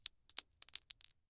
Bullet Shell Sounds
pistol_generic_1.ogg